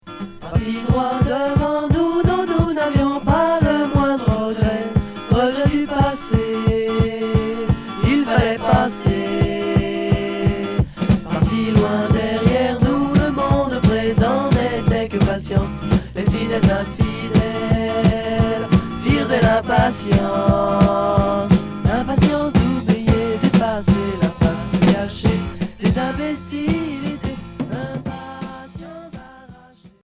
Folk rock